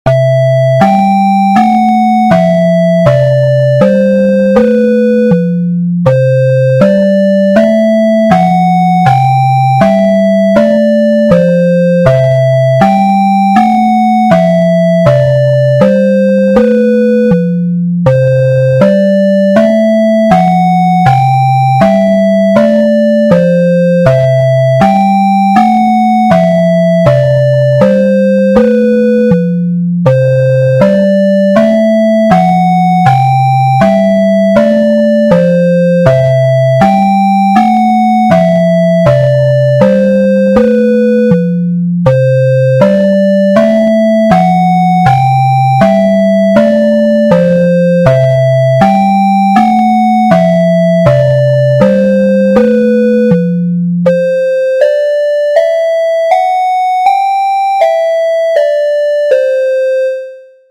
メルヘンの国とかのイメージです。ループ対応。
BPM80